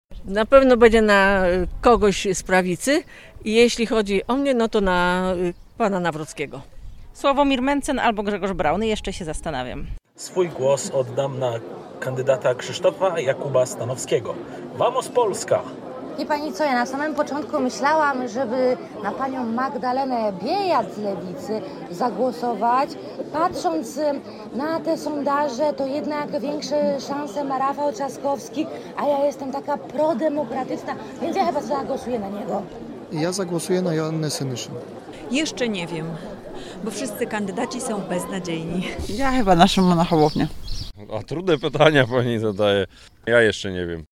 Tak wynika z naszej ulicznej sondy.